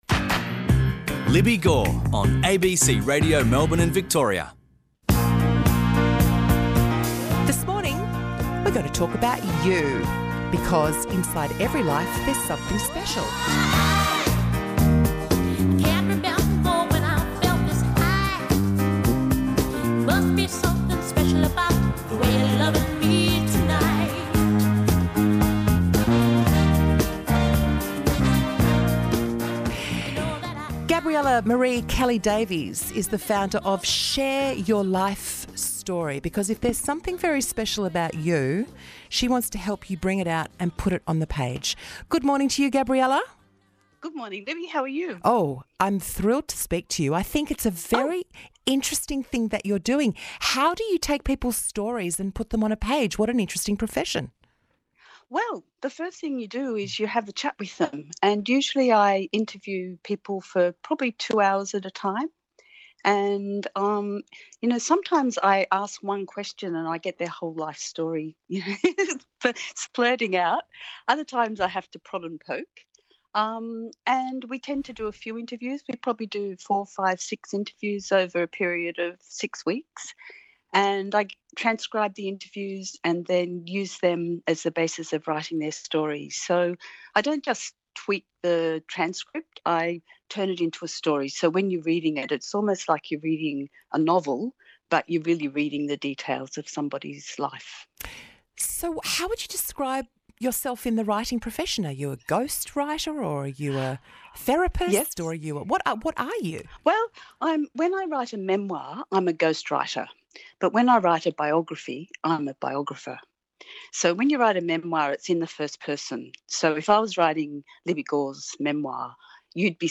I did an interview about writing a life story with the gorgeous Libbi Gorr this morning on ABC radio.